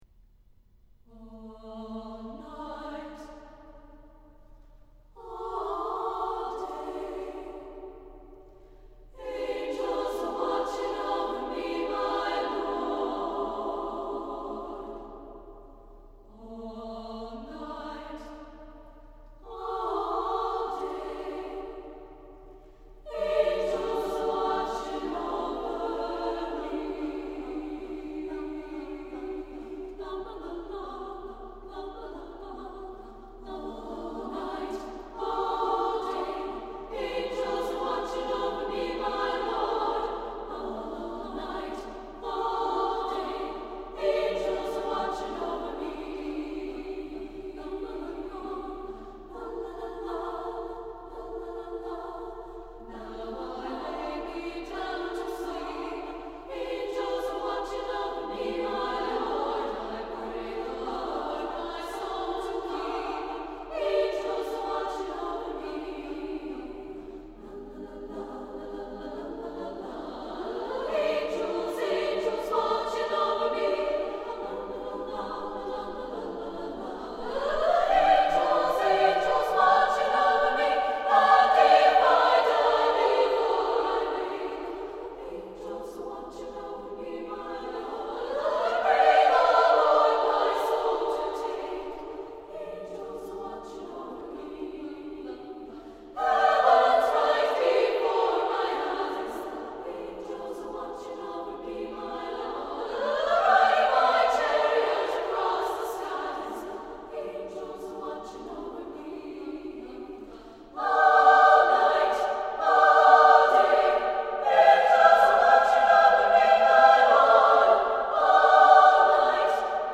for SSAA Chorus (2000-02)
The Spirit of Women is a set of three songs for Women's Chorus, a cappella.